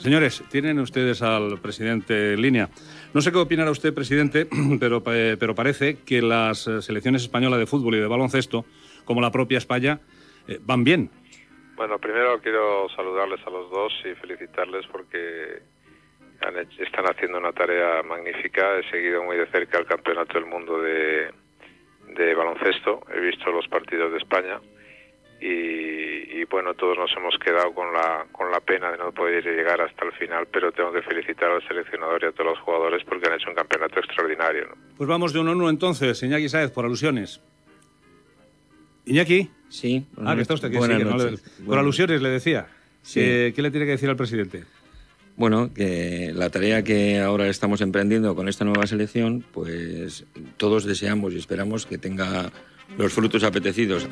Inici de la tertúlia del programa amb l'entrenador Iñaki Sáez.